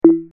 no_match_sfx.mp3